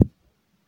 beeb kick 18
Tags: 808 drum cat kick kicks hip-hop